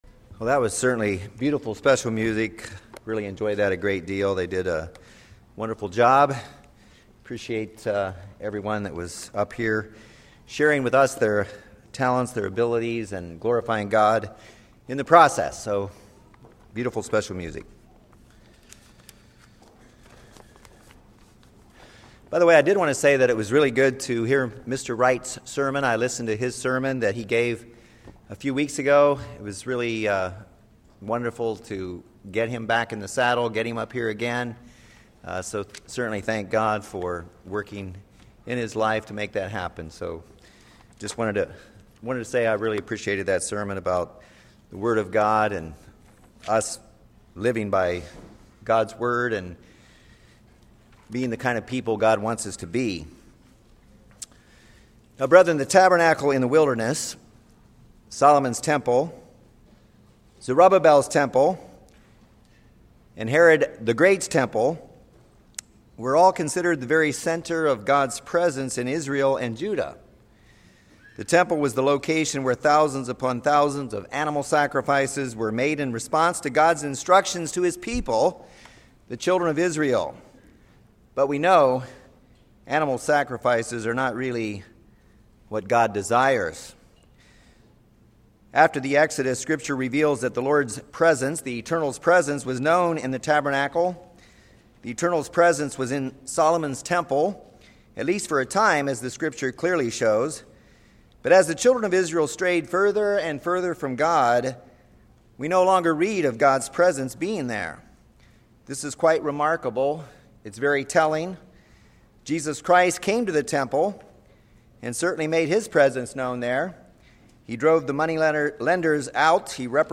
This sermon discusses seven principles to consider in regard to the building of God’s spiritual temple.